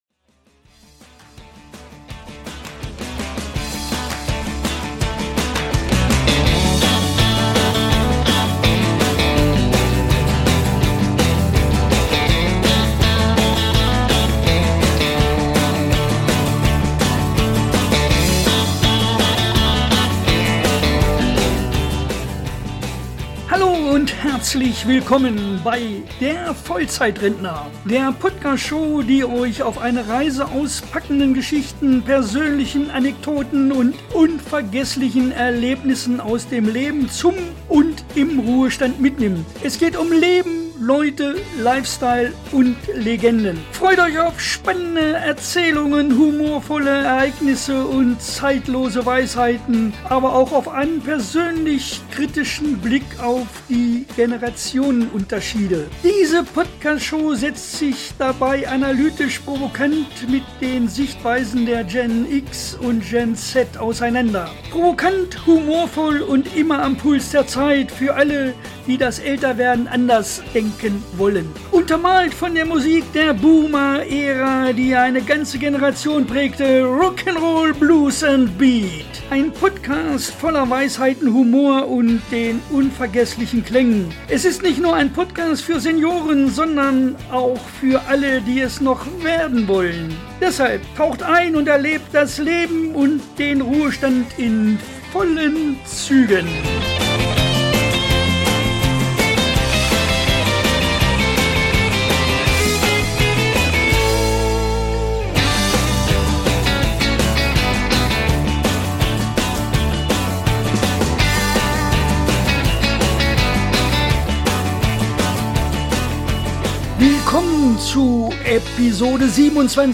Hier wird nicht nur geredet, hier wird durchgestartet: ehrlich, frech und mit Vollgas.